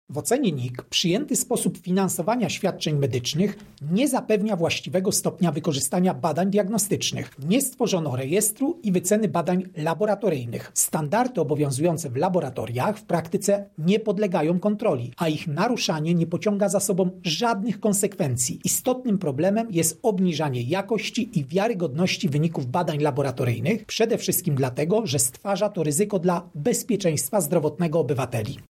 Więcej mówi Krzysztof Kwiatkowski, prezes Najwyższej Izby Kontroli.